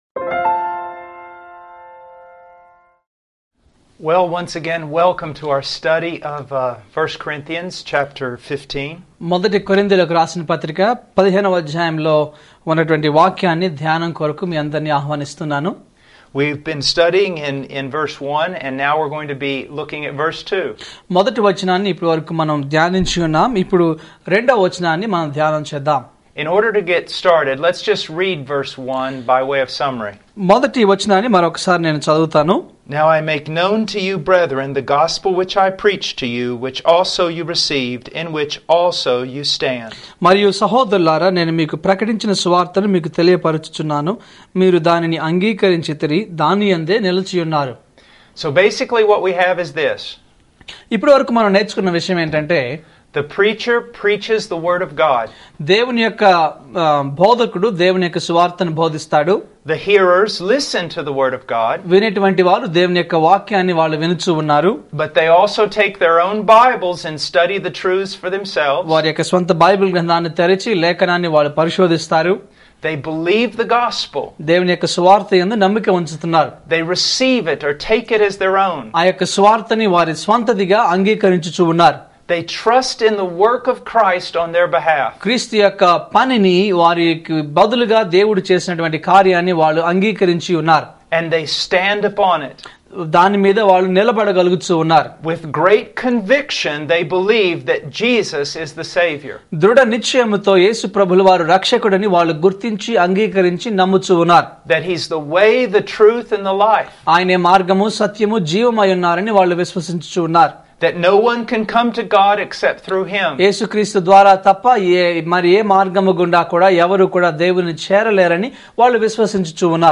ఫుల్ సేర్మోన్ (FS)